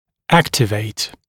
[‘æktɪveɪt][‘эктивэйт]активировать